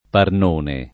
[ parn 1 ne ]